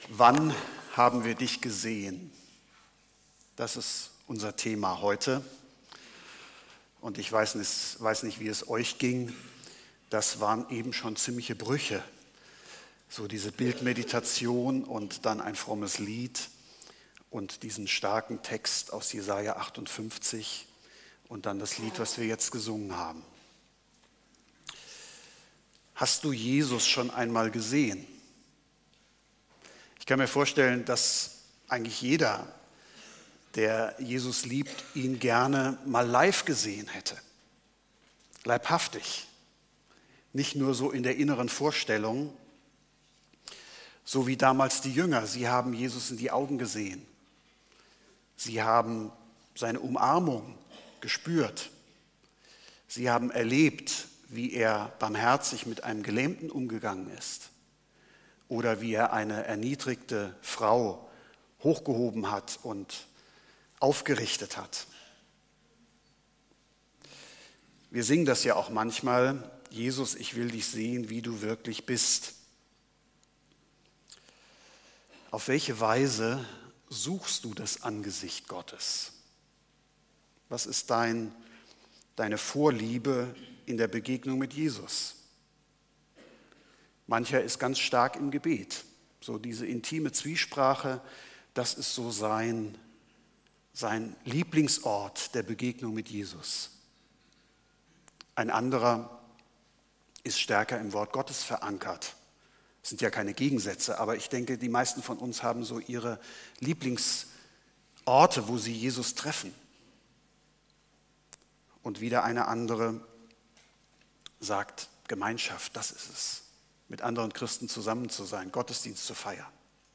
Gottesdienst